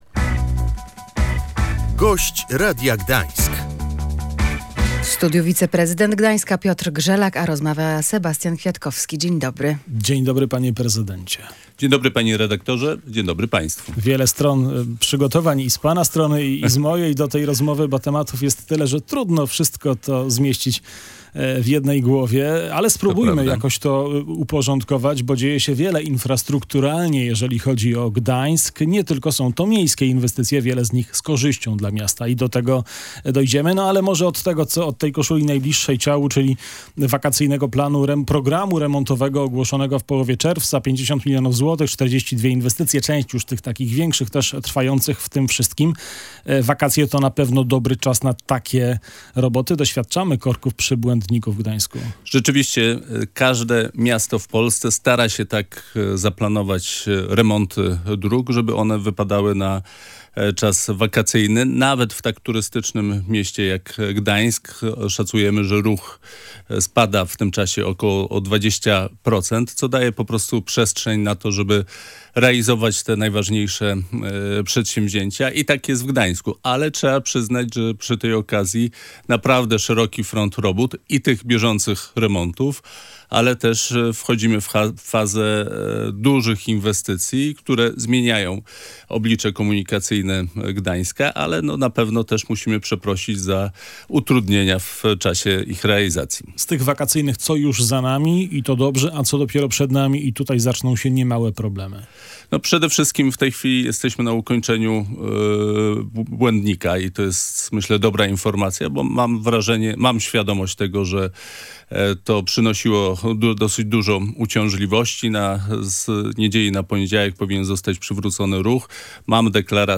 – Prace powinny zakończyć się zgodnie z terminem – mówił na antenie Radia Gdańsk Piotr Grzelak, wiceprezydent Gdańsk